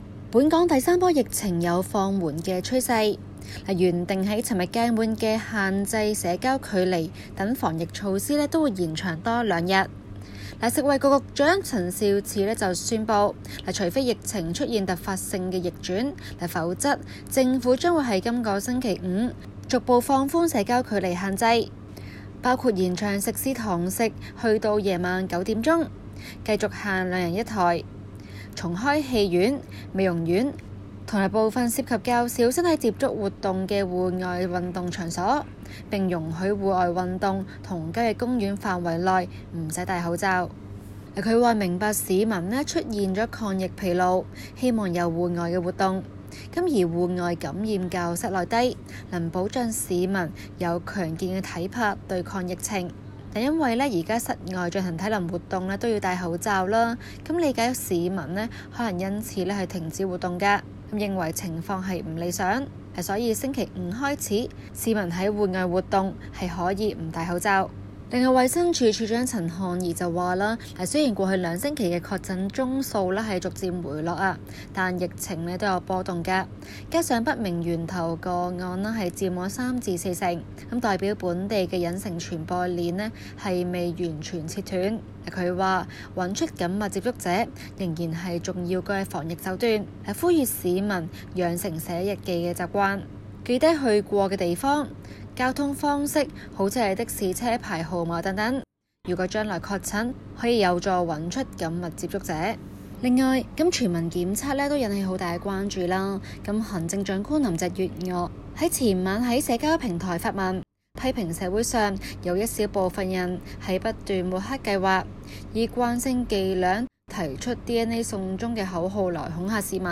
今期【中港快訊】環節報道港府有望在日內放寬防疫措施的限制，全民測試惹熱議。